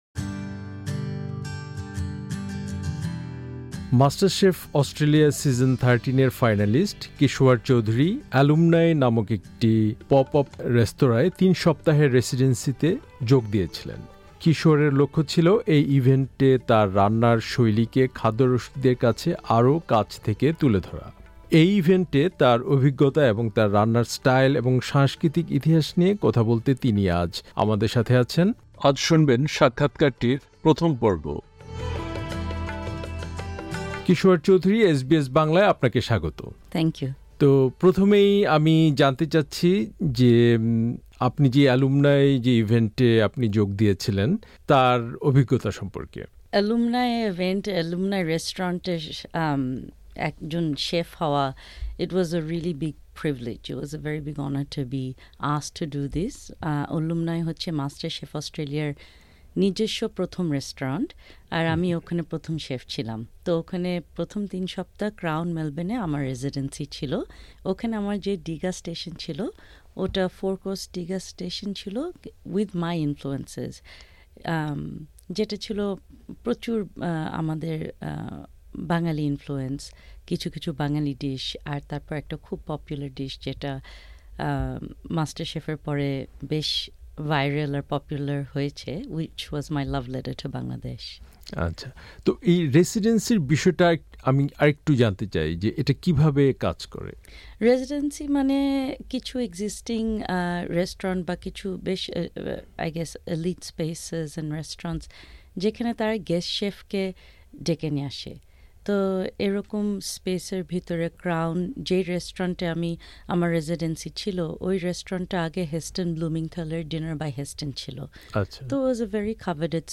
এলুমনাই ইভেন্টে কিশোয়ার তার অভিজ্ঞতা, রান্নার স্টাইল এবং এর সাংস্কৃতিক ইতিহাস নিয়ে কথা বলেছেন আমাদের সাথে। এখানে প্রকাশিত হল সাক্ষাৎকারটির প্রথম পর্ব।